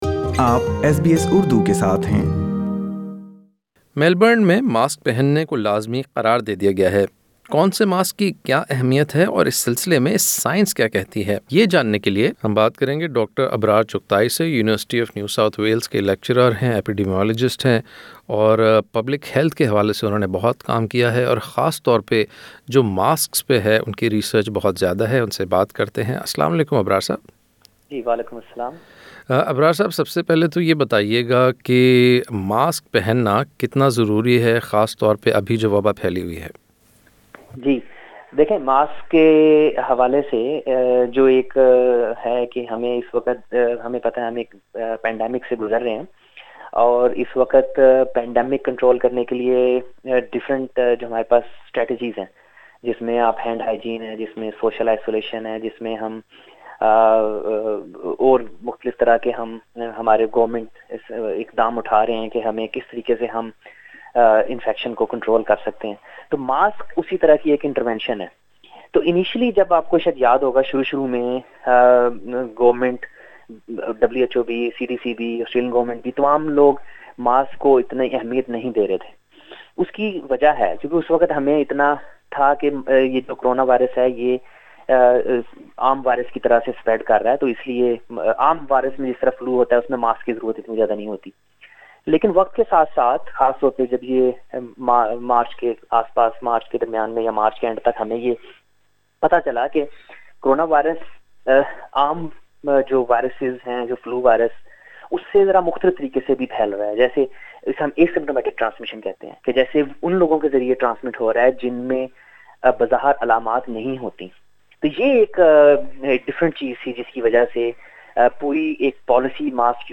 Surgical or cloth mask? We ask the scientist whose research is all about masks and infection prevention